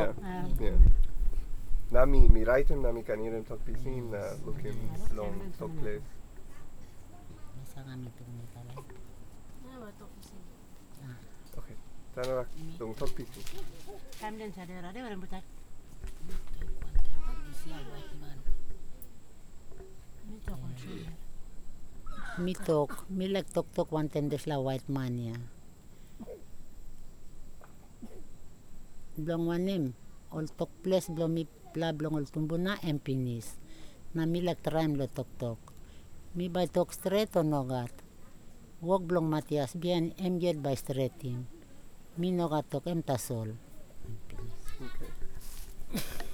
digital wav file recorded on Zoom H2n digital recorder
Sabente, Madang Province, Papua New Guinea